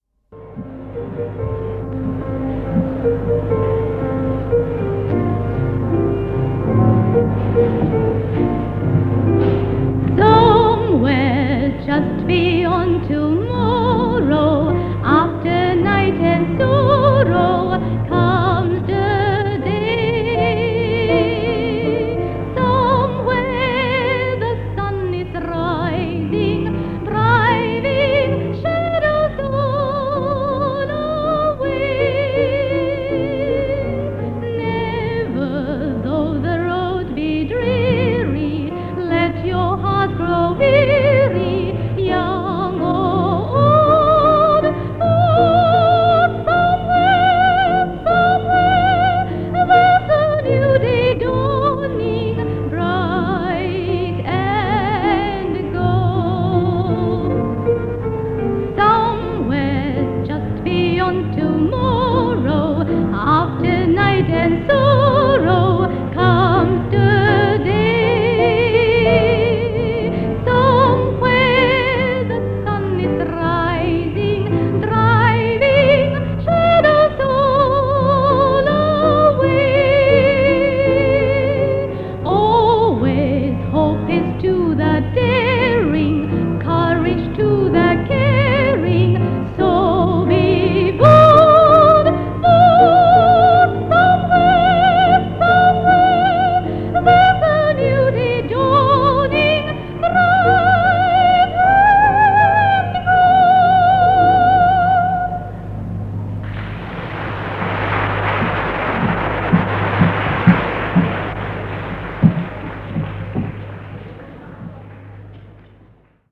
Location : Ipoh
This part features more songs from the MGS Singout of 1968.